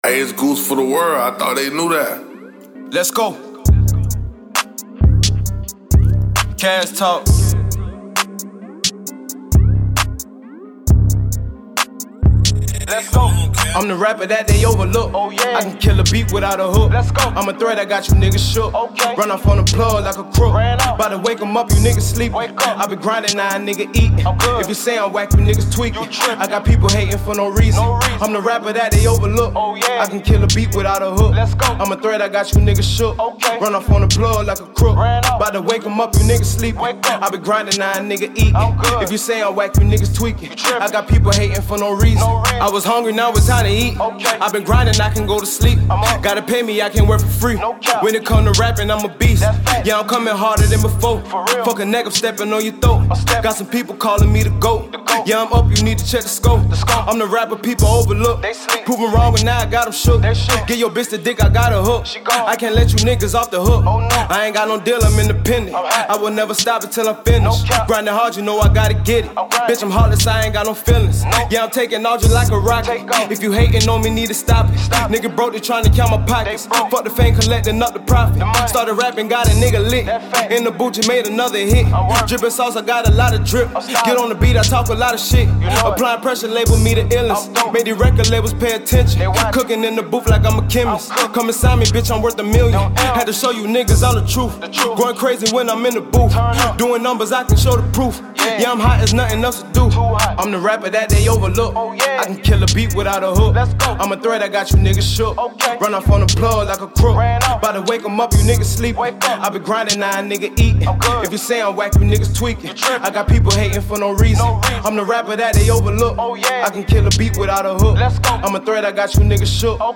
Hiphop
straight street Anthem
killin the beat with a bouncy saucy gritty flow